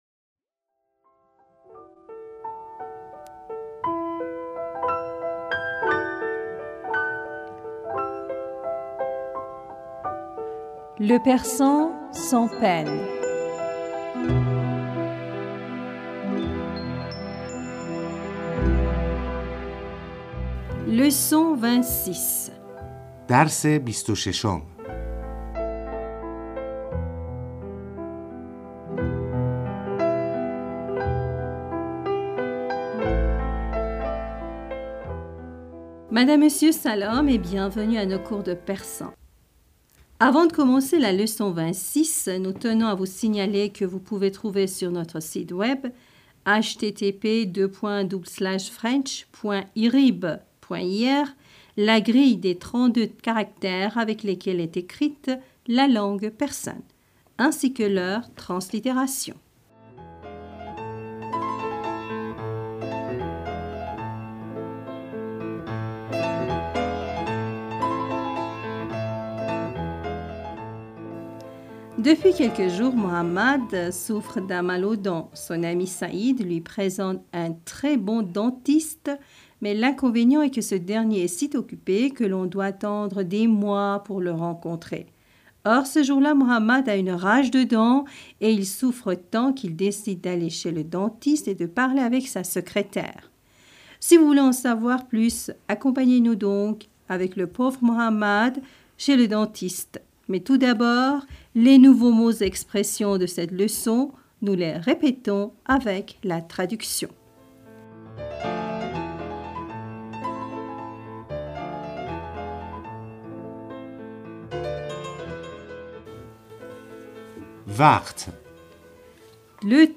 Madame, Monsieur, Salam et bienvenus à nos cours de persan.